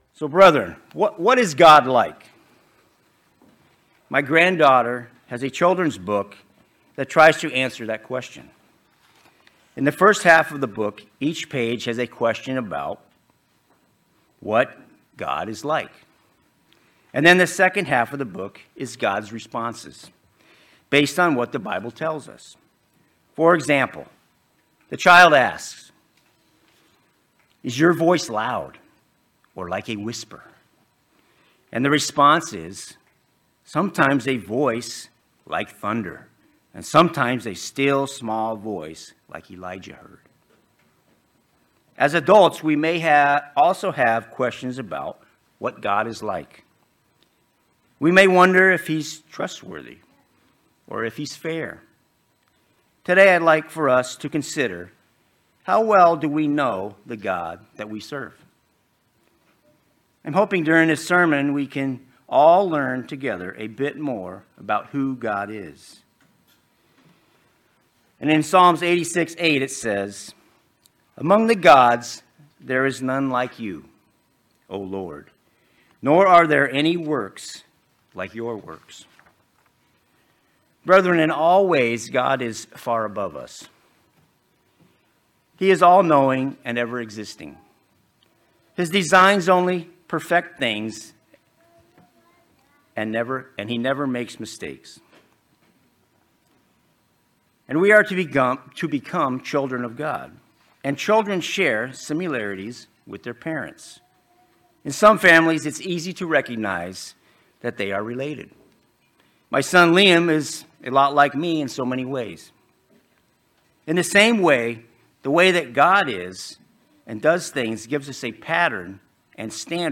Sermons
Given in Worcester, MA